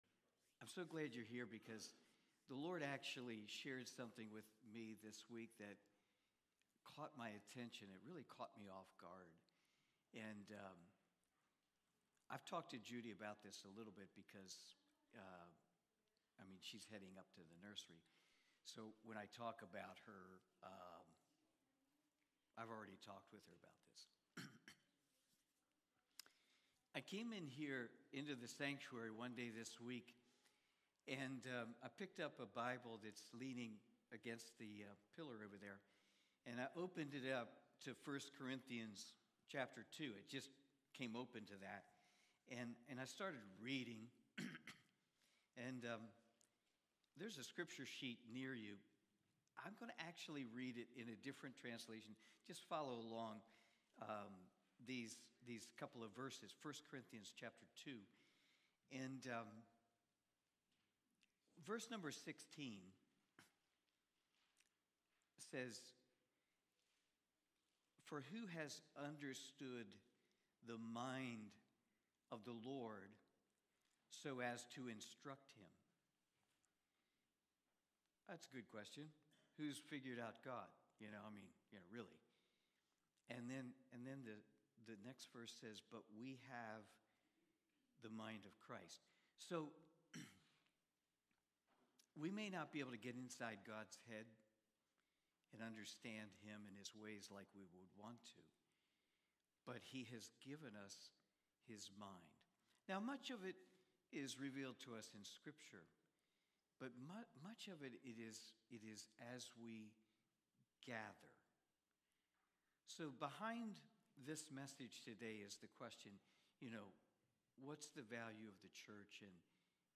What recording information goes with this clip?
1 Corinthians Watch Listen Save Cornerstone Fellowship Sunday morning service, livestreamed from Wormleysburg, PA.